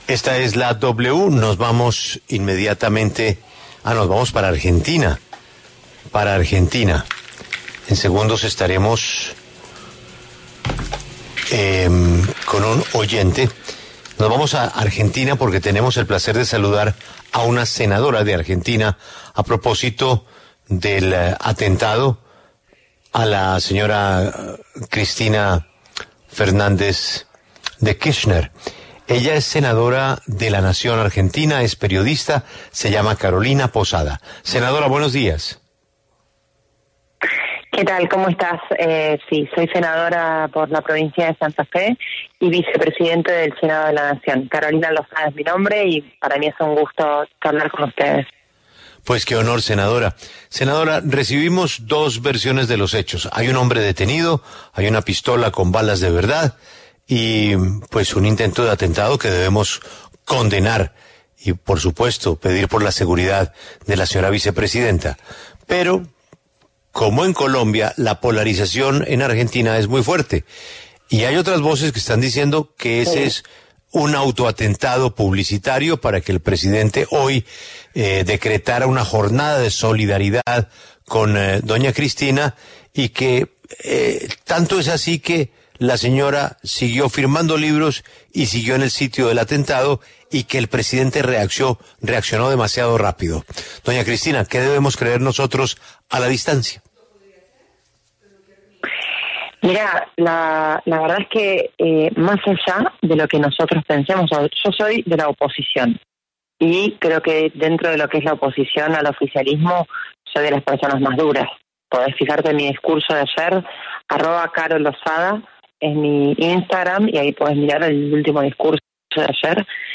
Carolina Losada, senadora de Argentina, rechazó lo sucedido en los micrófonos de La W e hizo un llamado a la cordura.
En entrevista con La W, la senadora Carolina Losada rechazó lo sucedido.